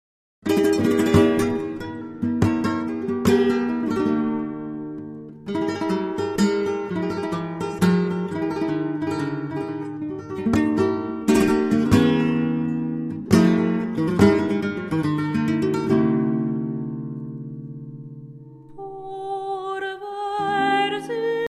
chanteuse française